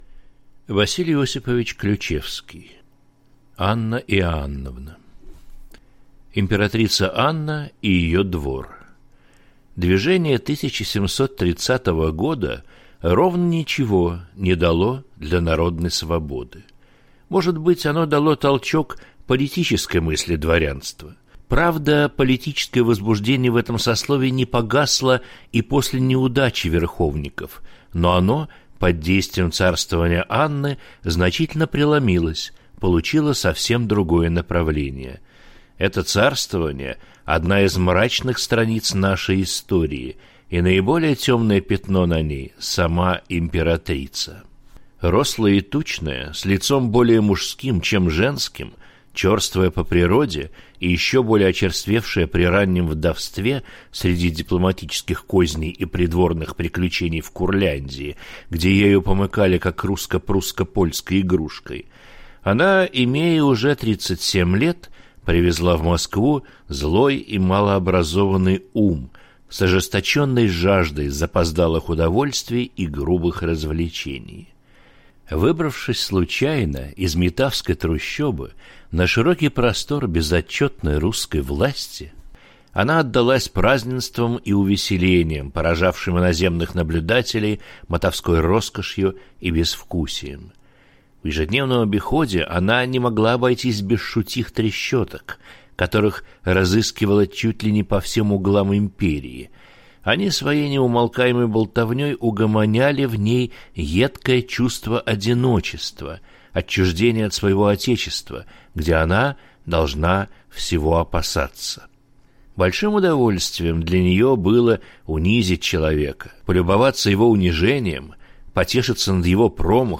Аудиокнига Анна Иоанновна | Библиотека аудиокниг
Прослушать и бесплатно скачать фрагмент аудиокниги